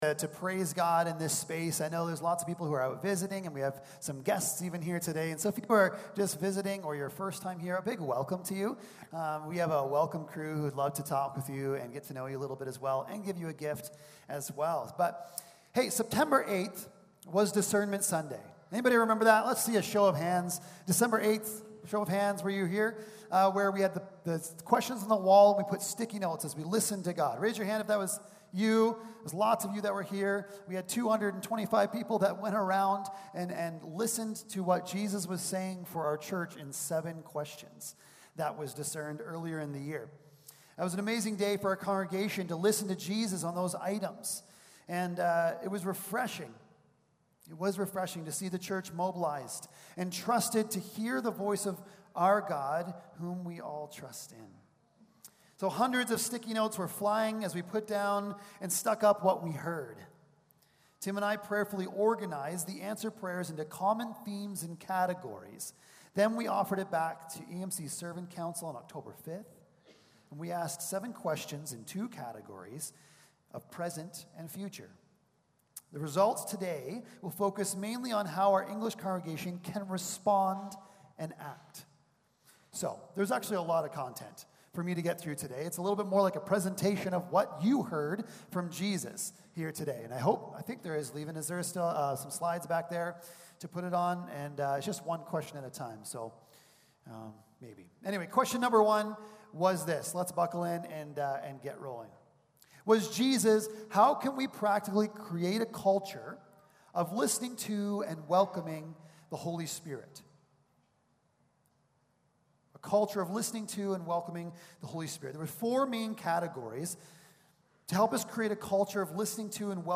Matthew 28:18-20 Service Type: Sunday Morning Service Passage